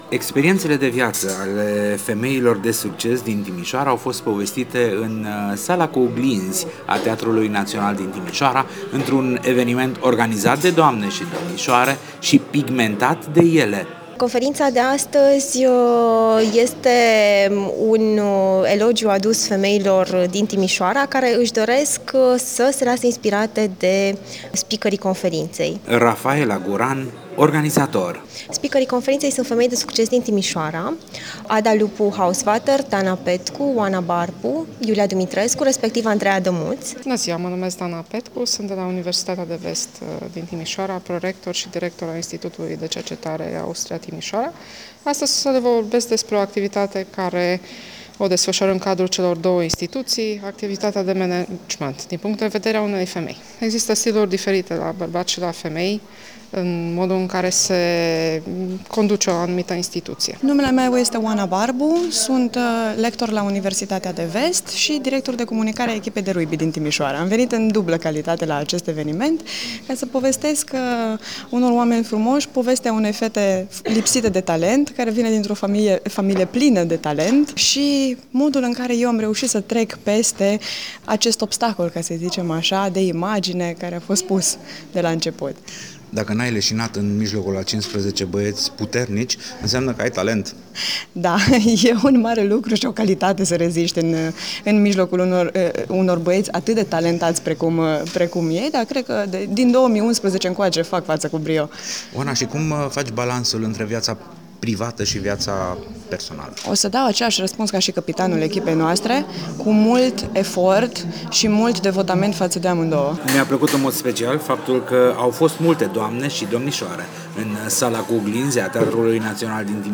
Sala cu Oglinzi a Teatrului National a gazduit un sir de povesti spuse de tinere doamne sau domnisoare in calea lor spre succes.
Reportaj_woman.mp3